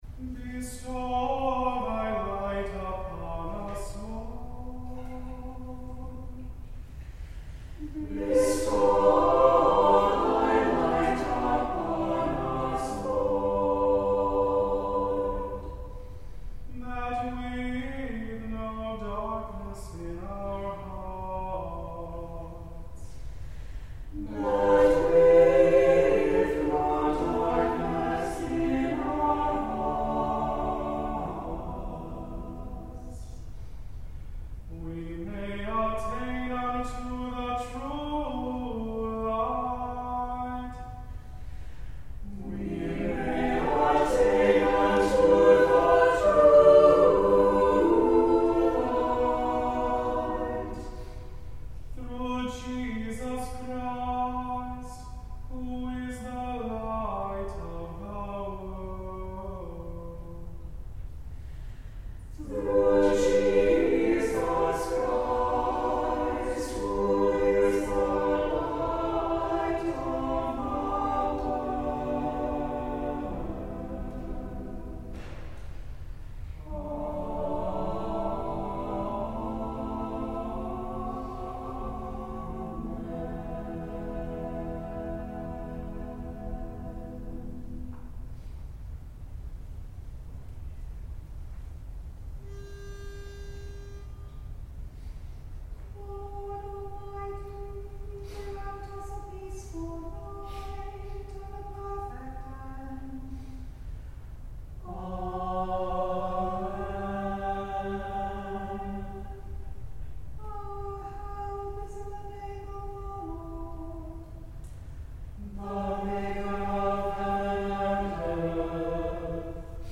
Worship and Sermons from Christ Episcopal Church in Little Rock, Arkansas
On Sundays, virtual and in-person services of prayers, scripture, and a sermon are at 8 a.m., 10:30 a.m., and 6 p.m., and a sung service of Compline begins at 7 p.m.